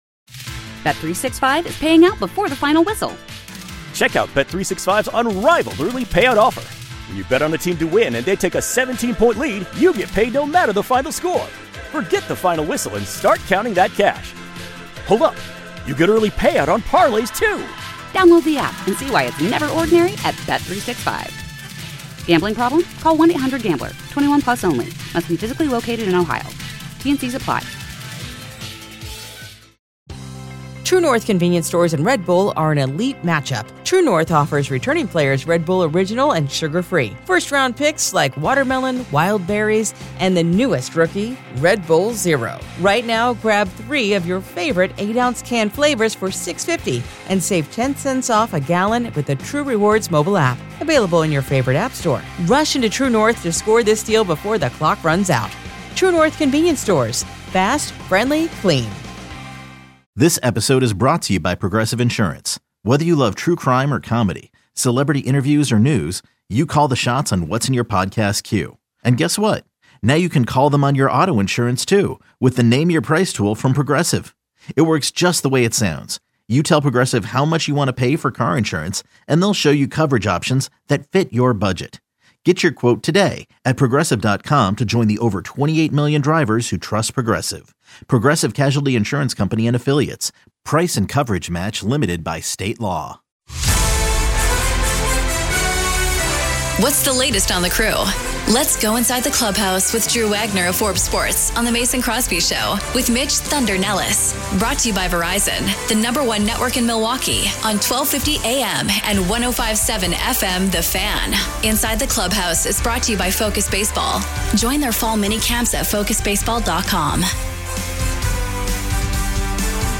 09-15-25 The Mason Crosby Show Interviews